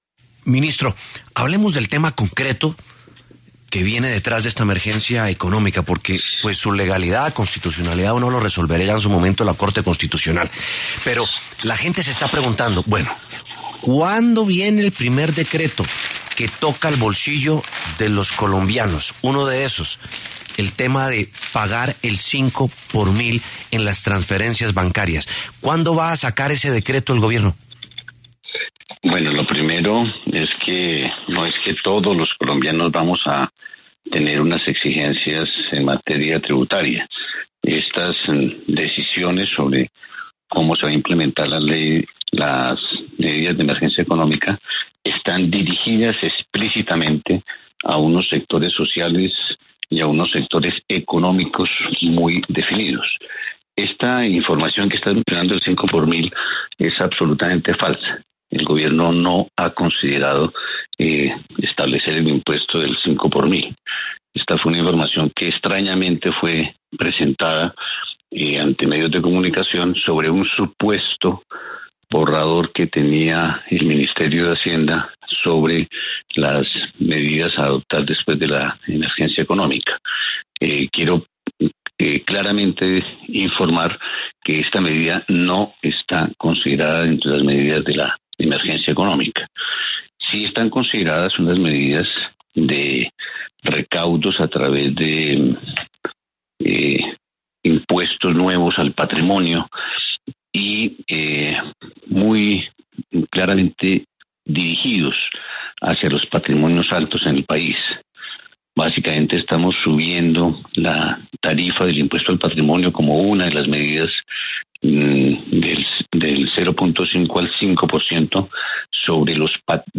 Germán Ávila, ministro de Hacienda, habló en La W y aseguró que la información sobre establecer el impuesto del 5x1.000 es “totalmente falsa”.
Germán Ávila Plazas, ministro de Hacienda, pasó por los micrófonos de La W y se refirió a la emergencia económica decretada por el Gobierno Nacional.